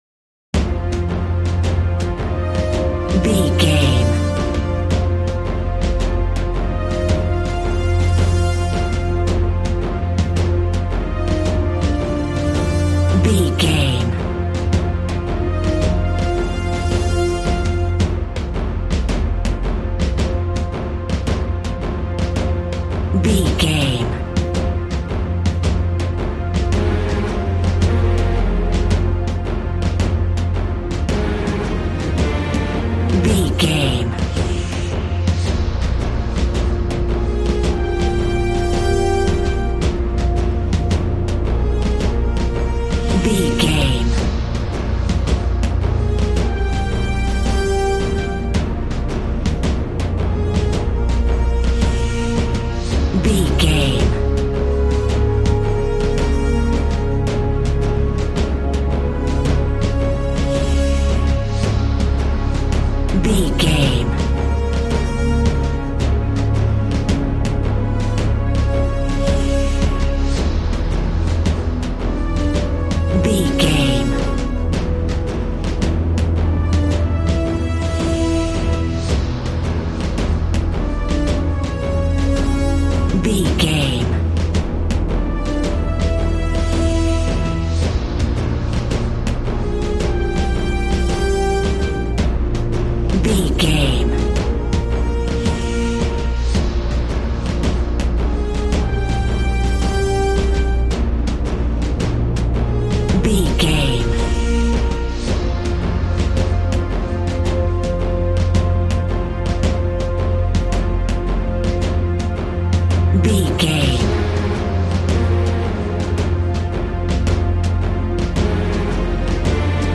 Epic / Action
Fast paced
In-crescendo
Aeolian/Minor
B♭
strings
brass
percussion
synthesiser